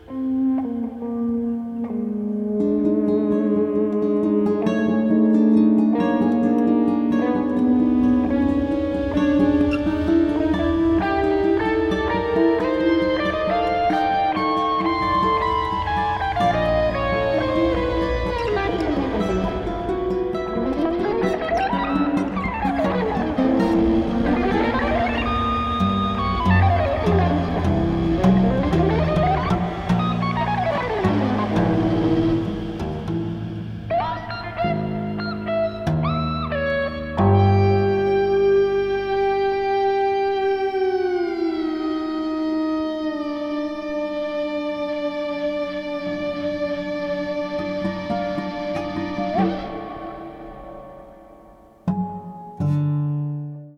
Electric guitar, Soundscapes, Live performance electronics